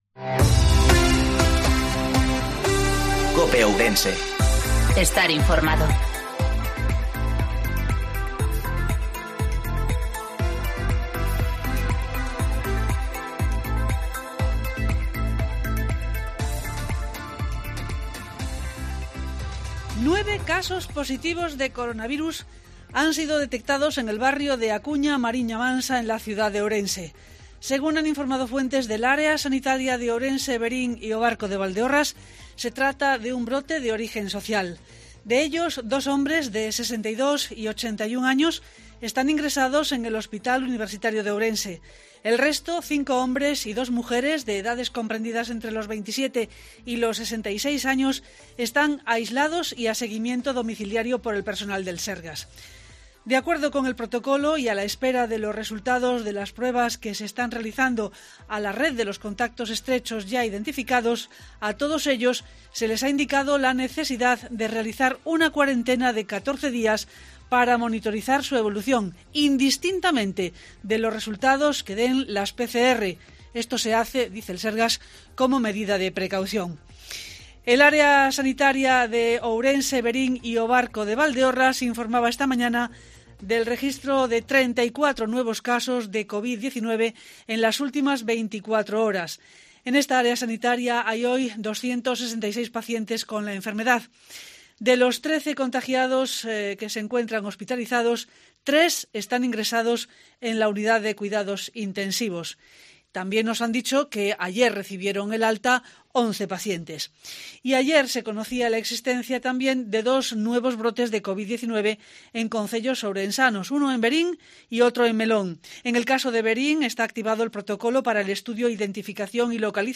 INFORMATIVO MEDIODIA EN COPE OURENSE